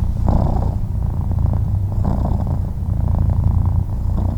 purr.wav